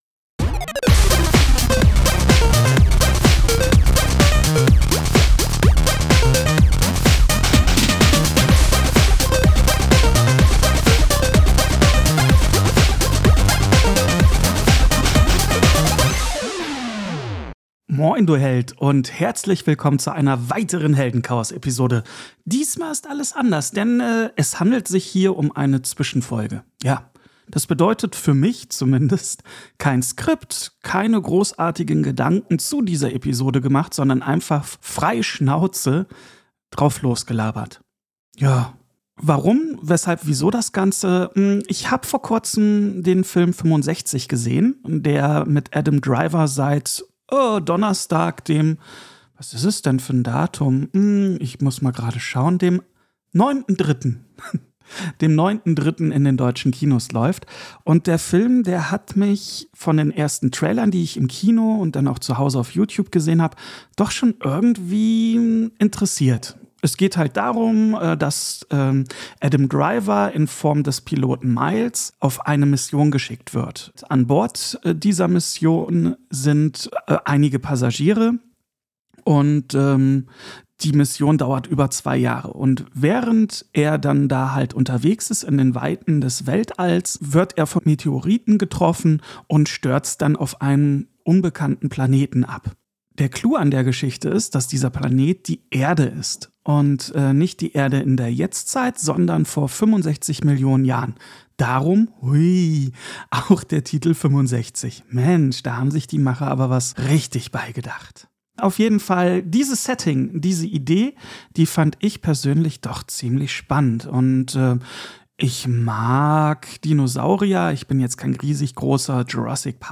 Abseits davon handelt es sich bei dieser Heldenchaos-Folge um eine spontane Aufnahme nach dem Motto "Frei Schnauze". Heißt: Kein Skript, einfach Mikro an und los!